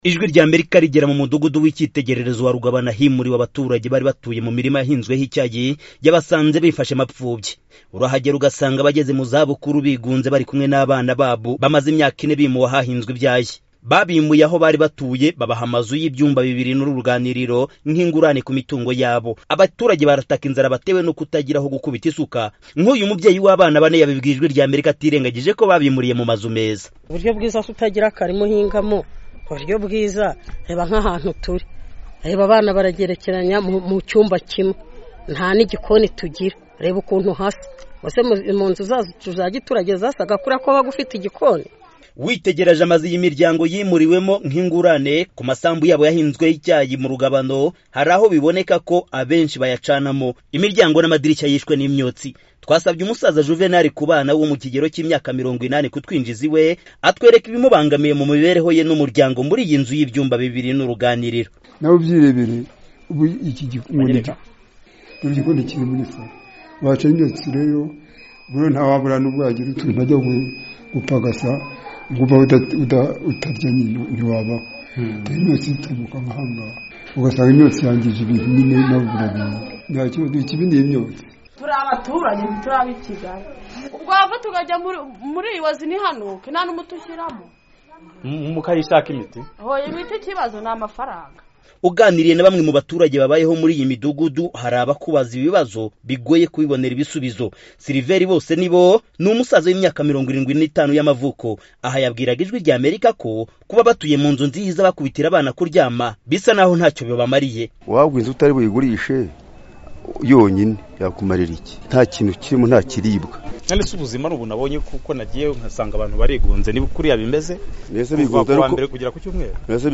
Inkuru icukumbuye